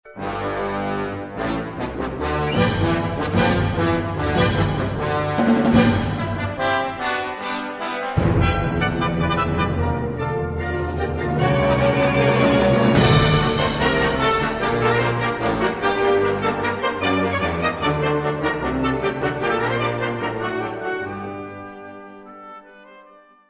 トロンボーンのハモリがとんでもなくカッコいいのだ。